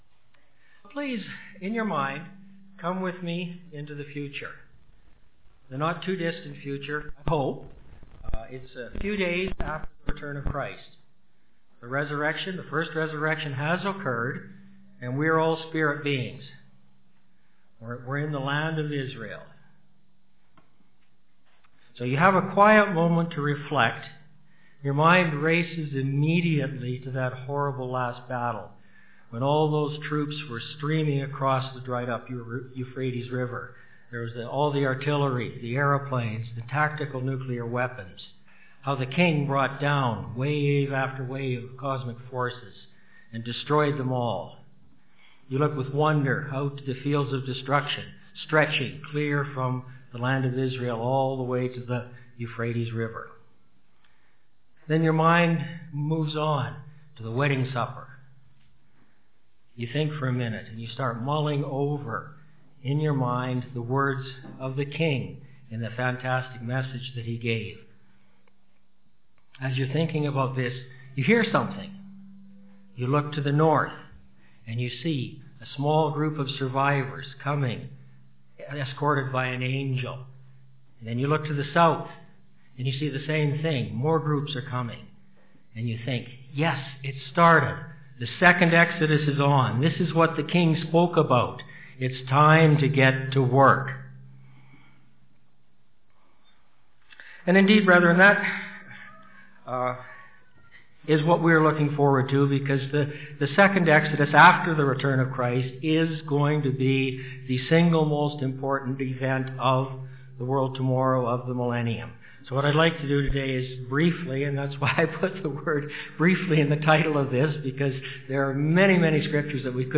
This sermon was given at the Canmore, Alberta 2016 Feast site.